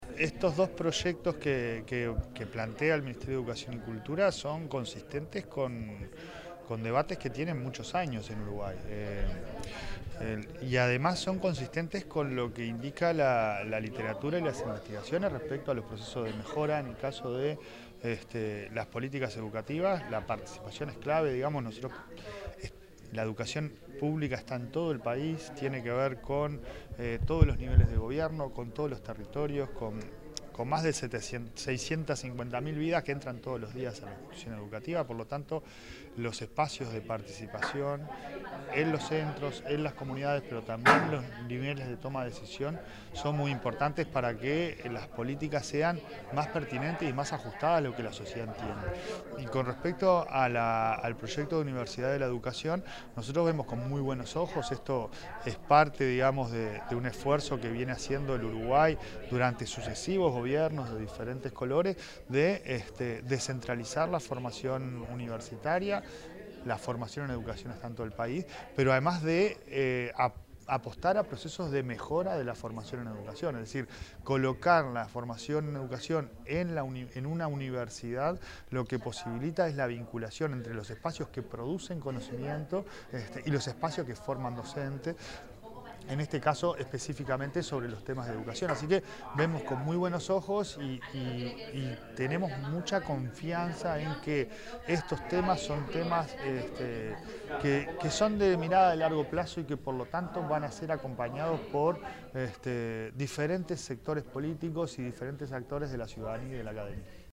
Declaraciones del presidente de la ANEP, Pablo Caggiani
El titular de la Administración Nacional de Educación Pública (ANEP), Pablo Caggiani, realizó declaraciones a la prensa acerca de los anteproyectos